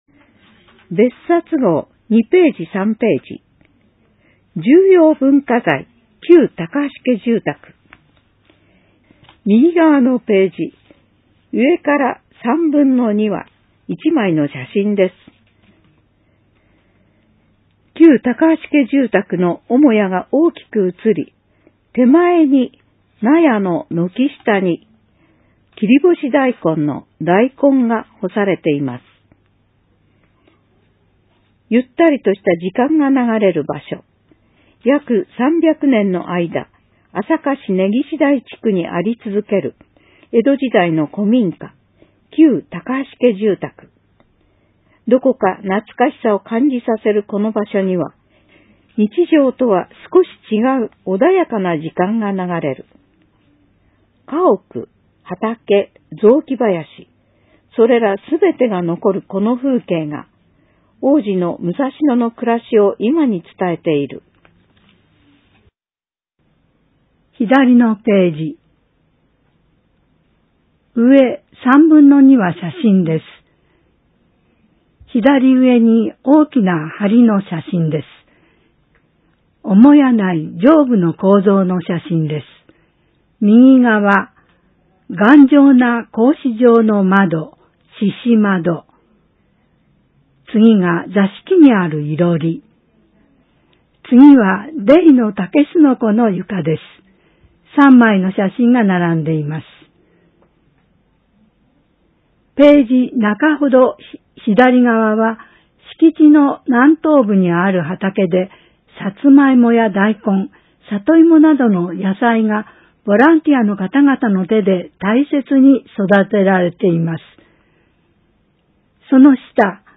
｢声の広報あさか」は、市内のボランティア「朝霞リーディングサークル」のご協力で、視覚に障害がある方のご自宅にＣＤ（デイジー形式）を郵送しています。